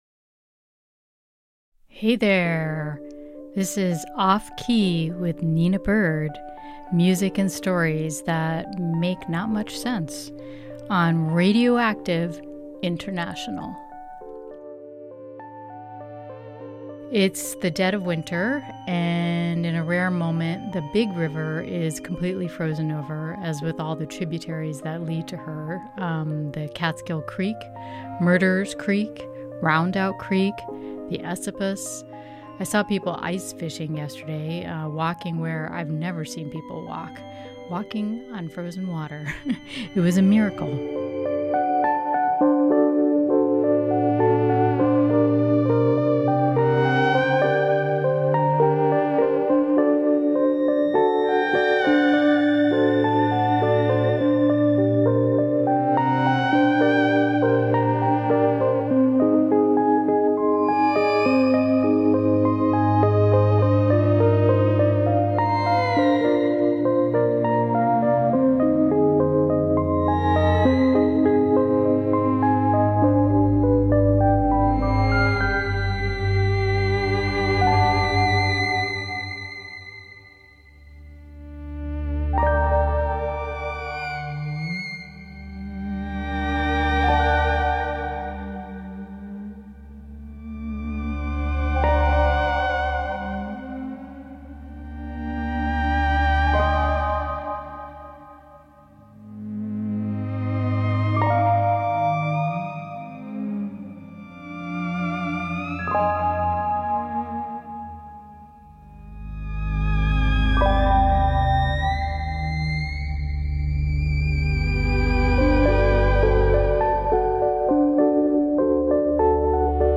Corsican nuthatch, France’s only endemic species